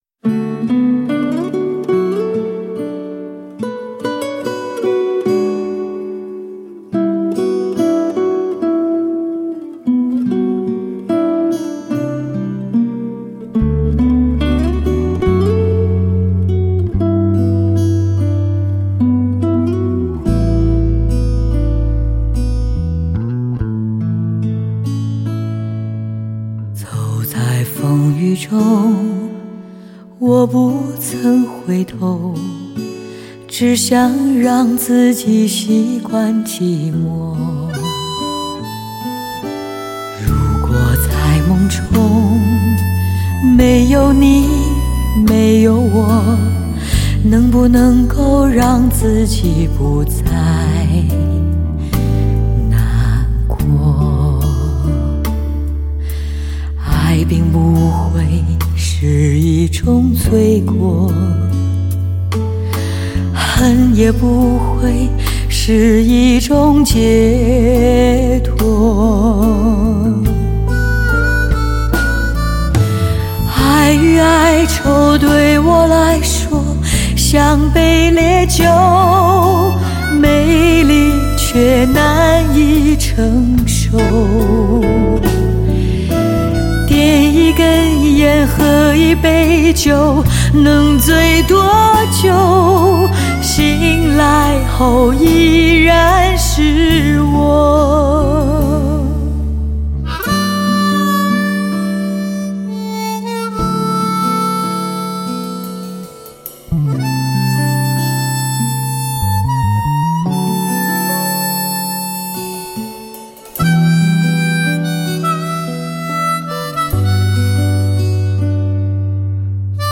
发烧唱片中最华美弥久的音符，雾色都市最醇美而耐人寻味的声音。
和宁静的夜一起倾听，如红酒弥留醇香的歌声……